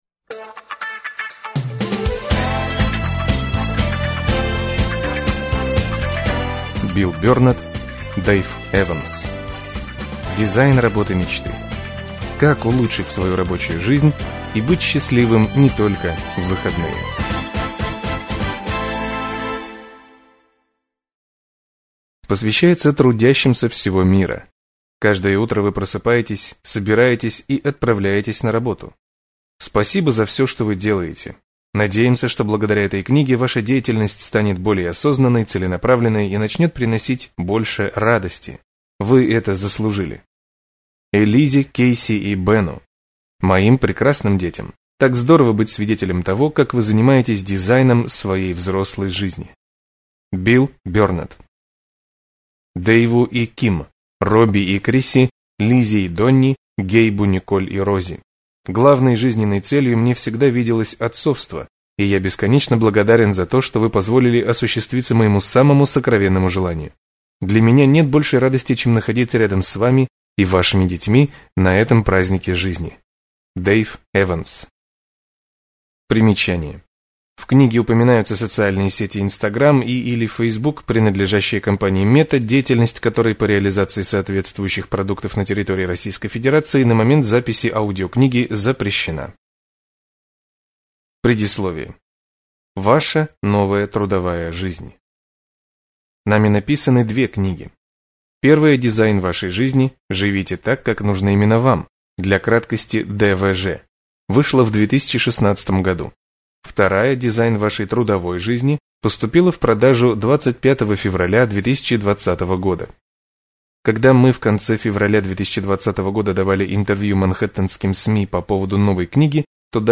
Аудиокнига Дизайн работы мечты: Как улучшить свою рабочую жизнь и быть счастливым не только в выходные | Библиотека аудиокниг